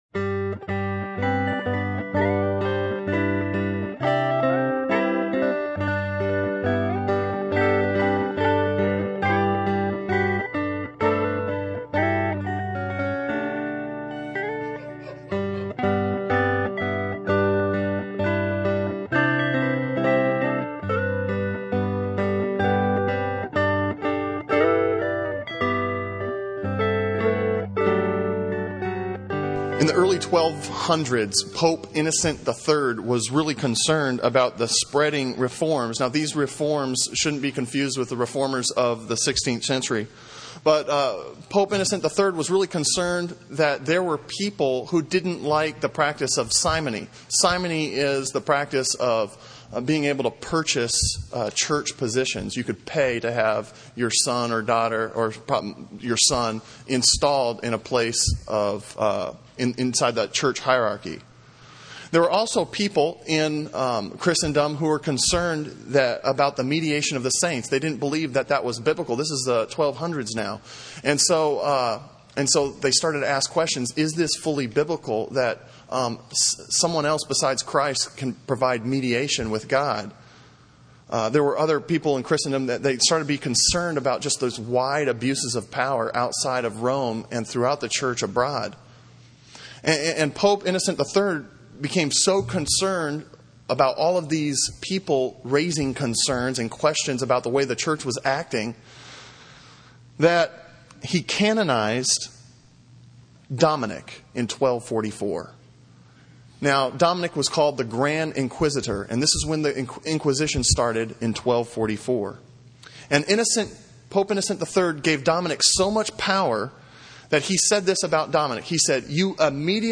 Sermon on 1 Thessalonians 1:1-10 from May 27, 2007